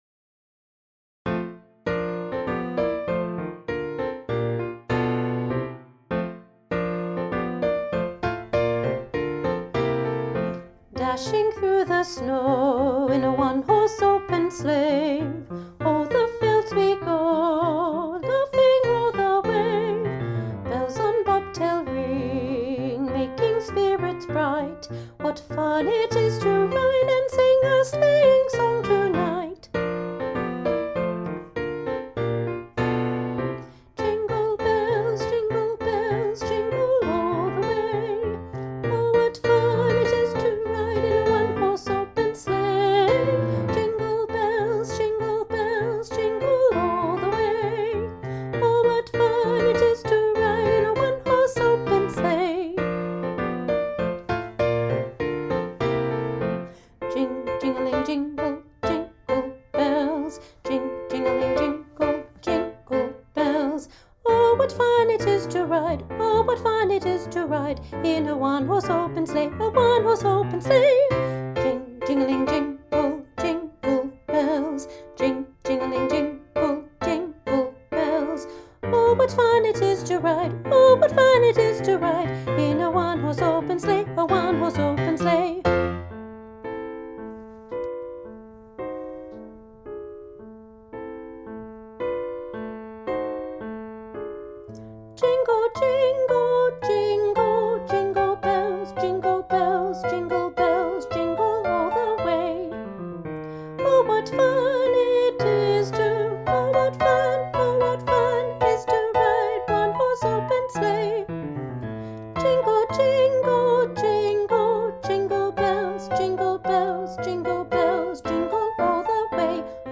Junior Choir – Nutcracker Jingles, Part 1
Junior-Choir-Nutcracker-Jingles-Part-1.mp3